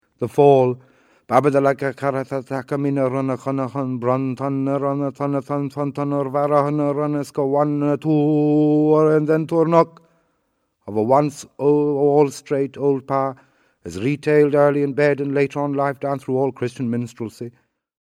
…and his garbled and prattling attempt at even the simplest thunderword (#5 on p.113) bears almost no resemblance to what Joyce wrote: